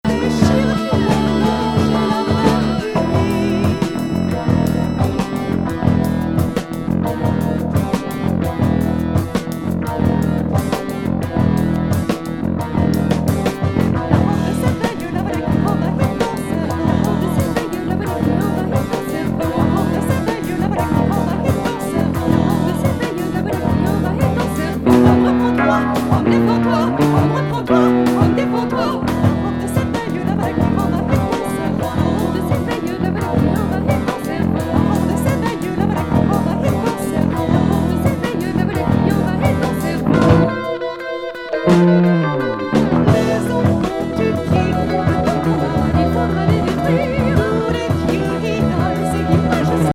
女性スキャット